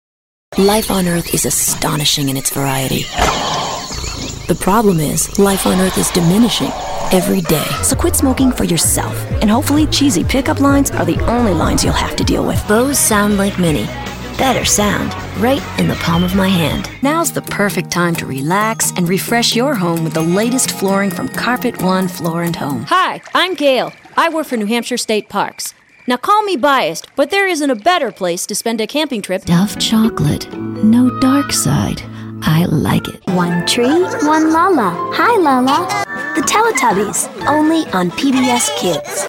Singer and Voice Actor.
Commercial
Established jingle singer and voiceover artist.